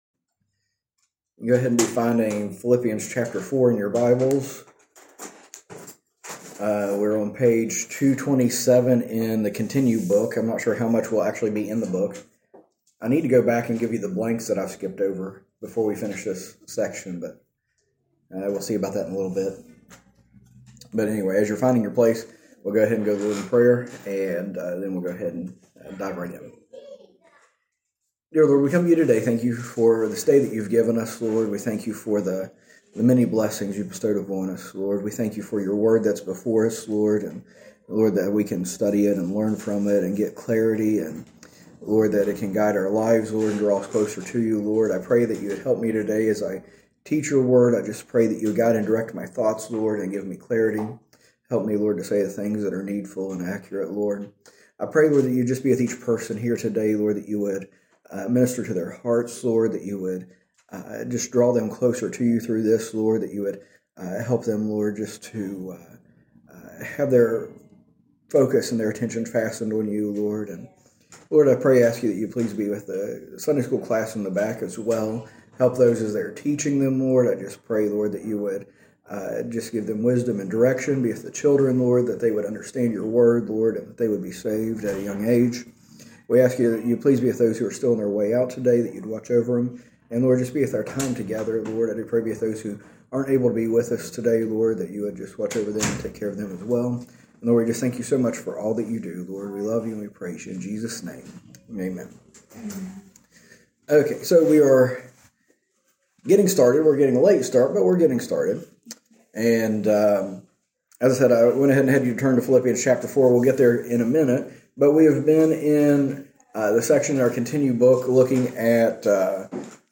A message from the series "Continue."